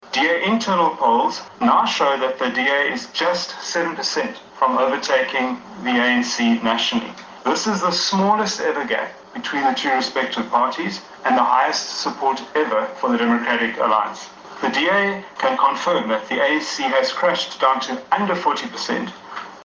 # Interne navolgingspeilings deur die DA onthul glo ‘n aansienlike verskuwing in die politieke dinamiek, met aanduidings van ‘n sewe-persent gaping nasionaal tussen die DA en die ANC. Die party se parlementslid Gregory Krumbock het die media in Kaapstad toegespreek en onhul sy party se steun staan op 32-persent, met die ANC op 40-persent, wat die kleinste gaping tot nog toe is: